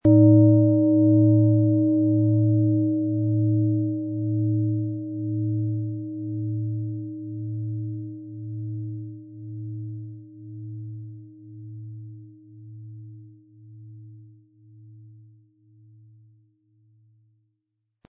• Mittlerer Ton: Eros
Ein die Schale gut klingend lassender Schlegel liegt kostenfrei bei, er lässt die Planetenklangschale Venus harmonisch und angenehm ertönen.
SchalenformBihar
MaterialBronze